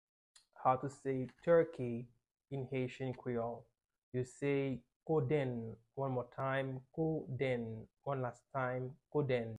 How to say "Turkey" in Haitian Creole - "Kodenn" pronunciation by a native Haitian Teacher
“Kodenn” Pronunciation in Haitian Creole by a native Haitian can be heard in the audio here or in the video below: